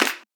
Claps
CLAP3.wav